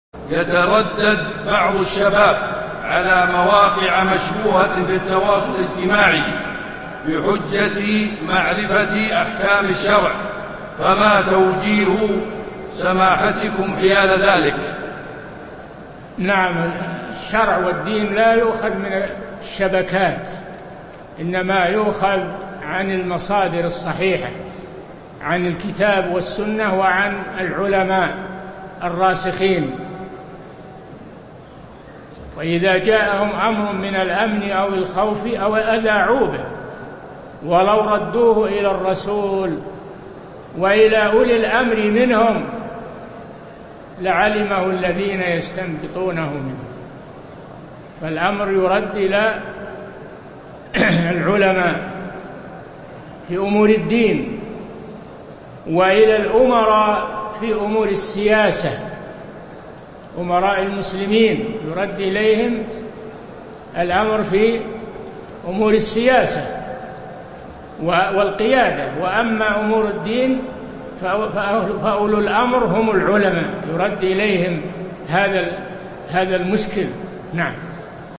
Download audio file Downloaded: 593 Played: 141 Artist: الشيخ صالح الفوزان Title: الشرع والدين والسياسة لا تُؤخذ من المواقع والشبكات المشبوهة Album: موقع النهج الواضح Length: 1:18 minutes (383.63 KB) Format: MP3 Mono 22kHz 32Kbps (VBR)